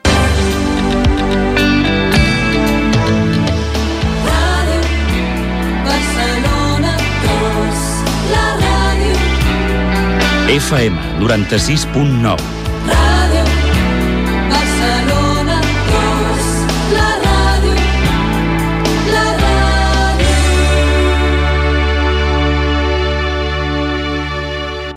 78b2f6be2080e0cf2dfffe981245a3928faf371b.mp3 Títol Ràdio Barcelona 2 Emissora Ràdio Barcelona 2 Cadena SER Titularitat Privada estatal Descripció Indicatiu de l'emissora als 96.9 MHz de l' FM de Barcelona.